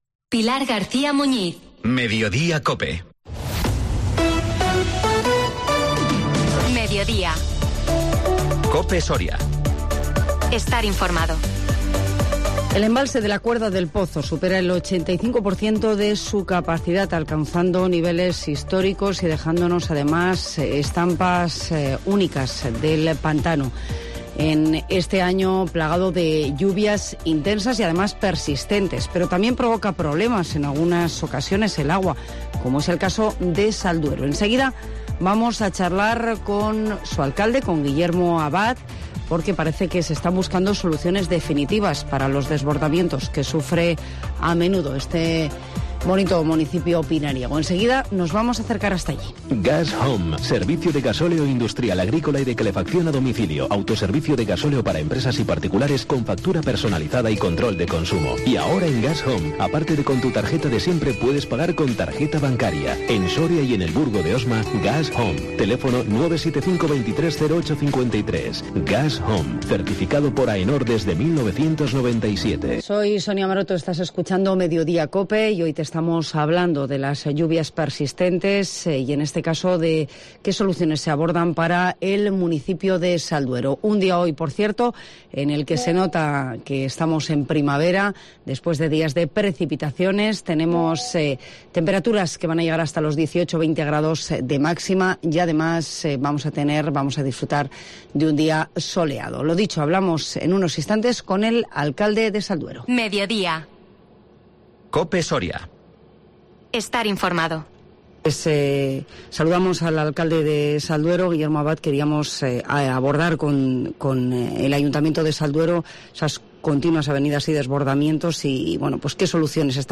Hoy en COPE Soria hablamos con el alcalde de Salduero sobre las continuas crecidas que inundan el municipio y las soluciones que se plantean y conocemos los primeros pasos de MAGIN, el Movimiento de Agricultores y Ganaderos Independientes.